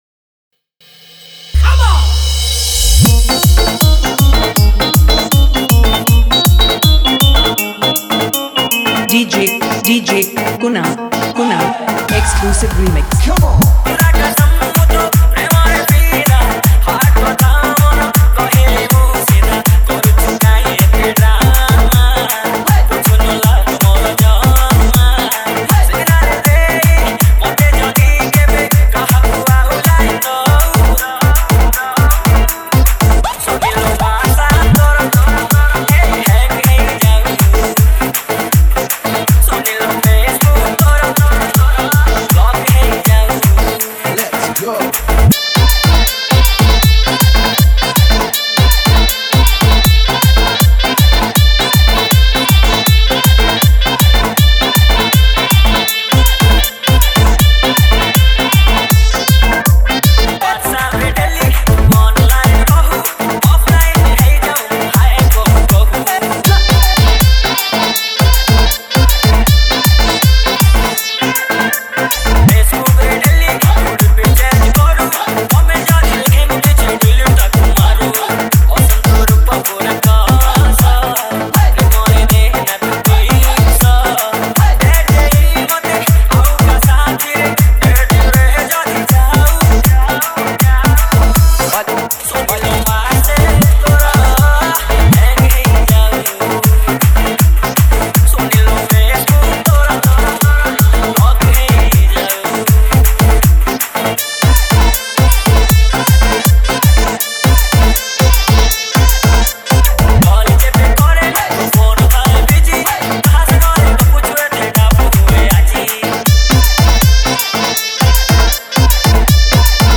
Category:  Odia New Dj Song 2019